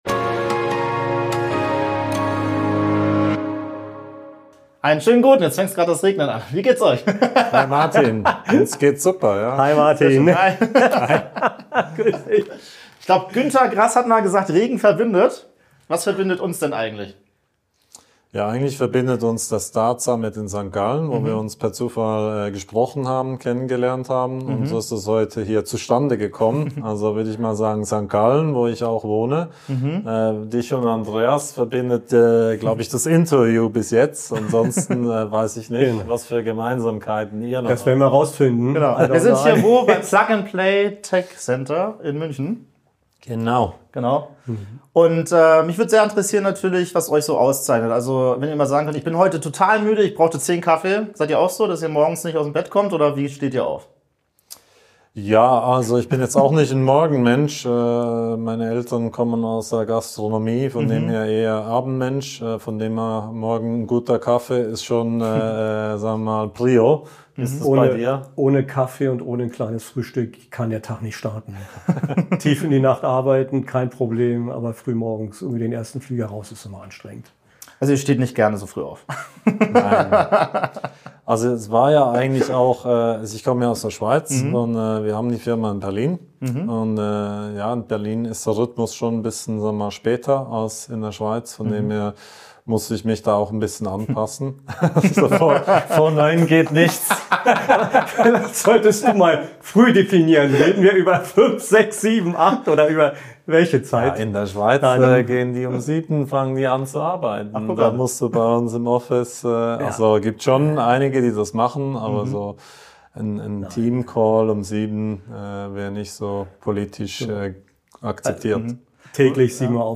Zusammenfassung Das Gespräch findet im Plug & Play Tech Center in München statt.